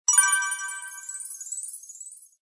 GoldCoin.mp3